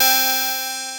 DOBLEAD C5-L.wav